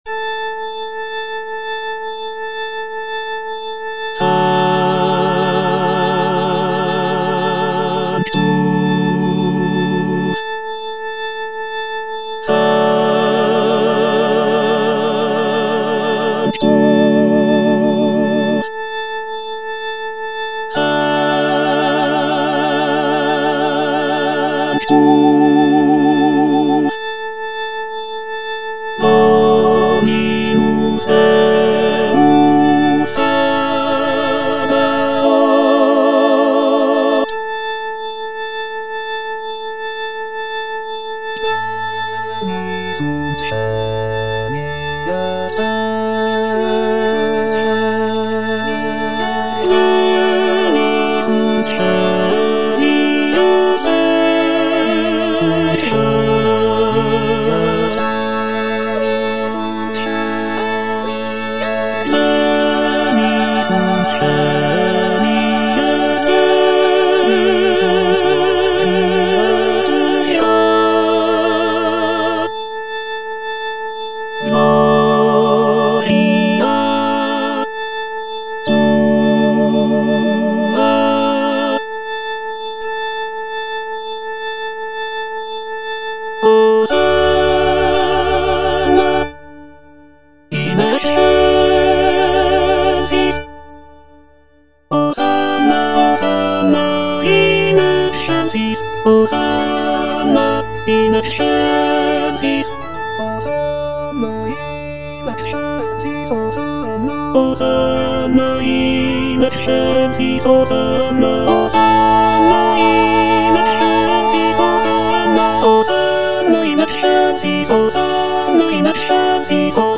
F. VON SUPPÈ - MISSA PRO DEFUNCTIS/REQUIEM Sanctus (tenor II) (Emphasised voice and other voices) Ads stop: auto-stop